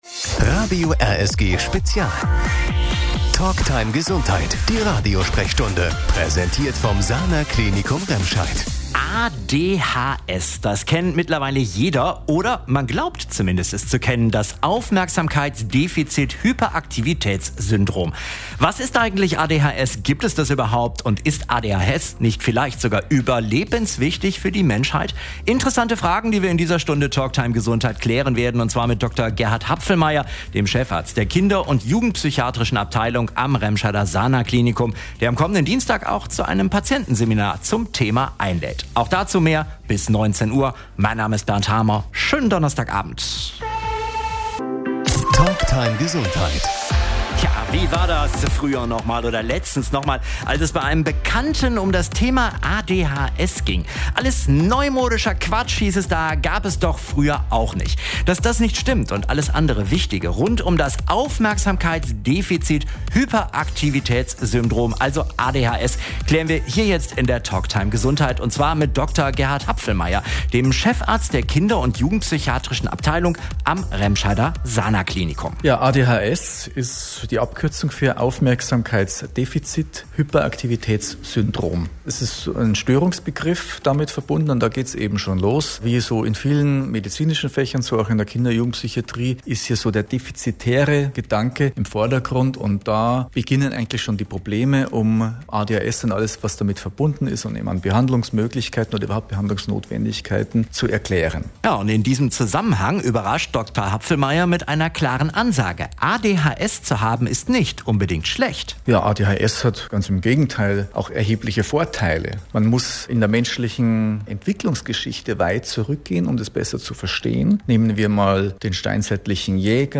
Die Radiosprechstunde stellte deshalb die Frage(n): Gibt es ADHS überhaupt?